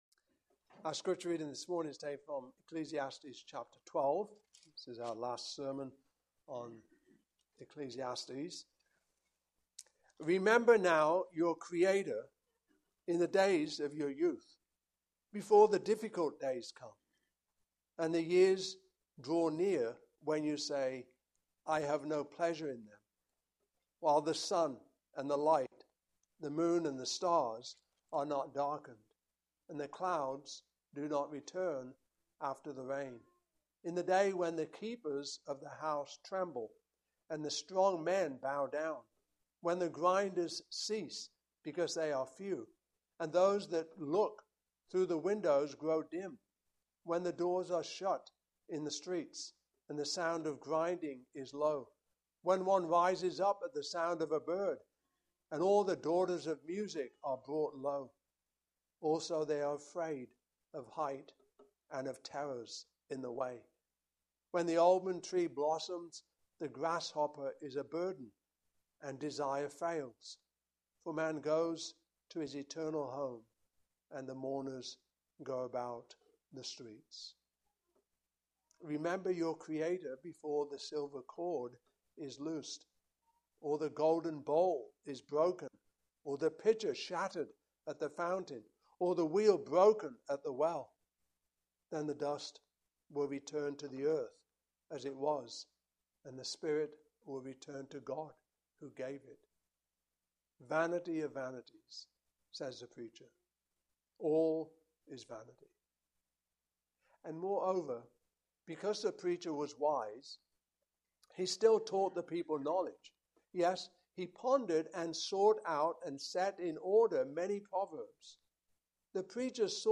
Passage: Ecclesiastes 12:1-14 Service Type: Morning Service